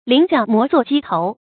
菱角磨作鸡头 líng jiǎo mó zuò jī tóu
菱角磨作鸡头发音